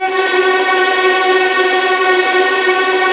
Index of /90_sSampleCDs/Roland - String Master Series/STR_Vlns Tremelo/STR_Vls Trem wh%